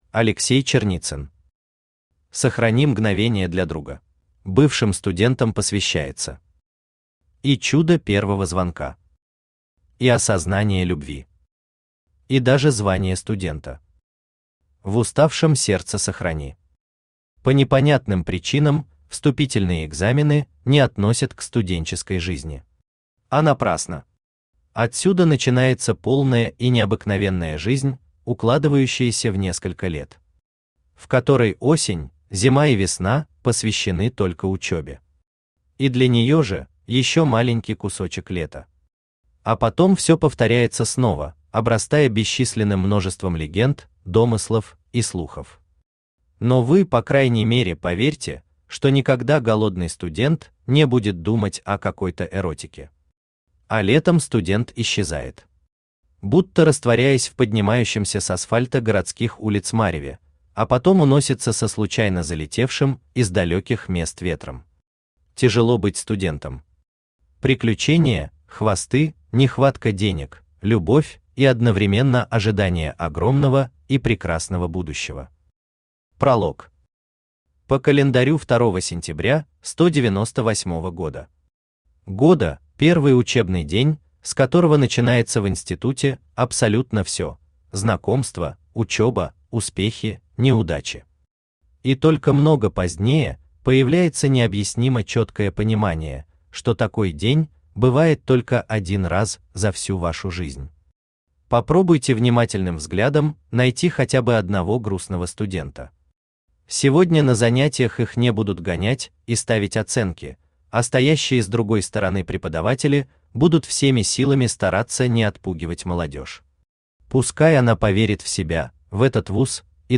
Аудиокнига Сохрани мгновения для друга | Библиотека аудиокниг
Aудиокнига Сохрани мгновения для друга Автор Алексей Черницын Читает аудиокнигу Авточтец ЛитРес.